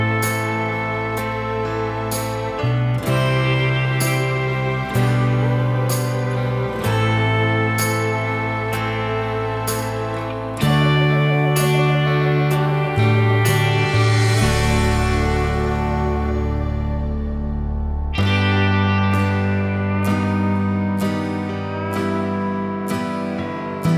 no Backing Vocals Country (Male) 2:50 Buy £1.50